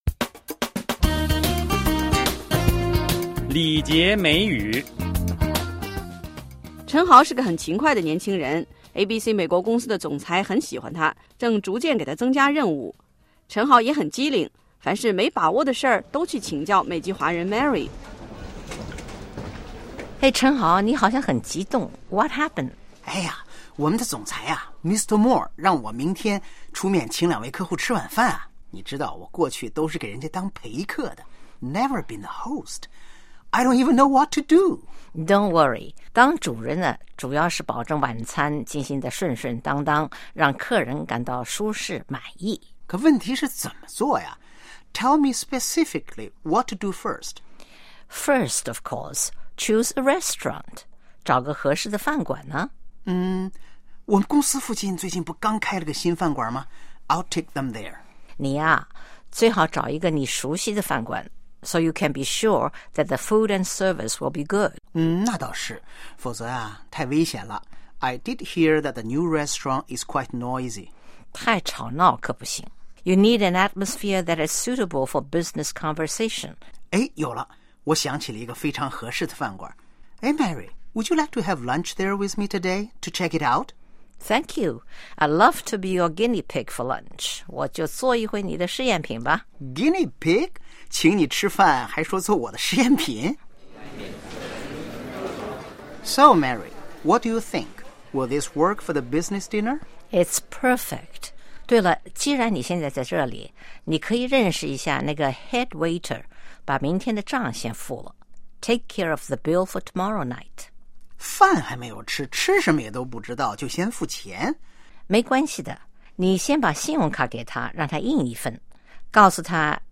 (Office ambience)